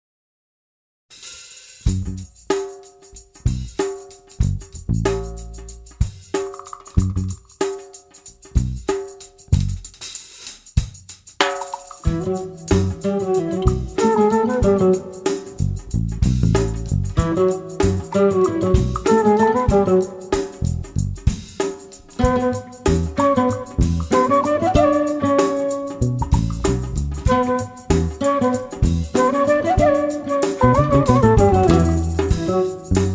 Genre: World Fusion.
bansuri
guitar
drums & percussion
tabla
double bass & electric bass
Recorded at Livingstone Studios, London